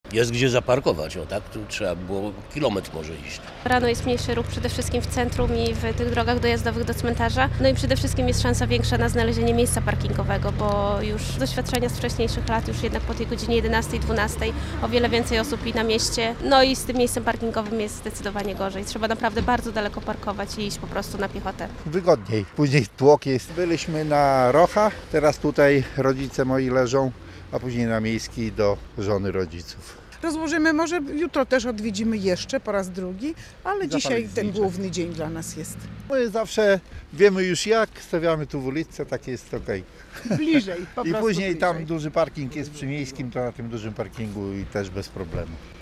Wzmożony ruch na podlaskich drogach i zmieniona organizacja ruchu przy nekropoliach - relacja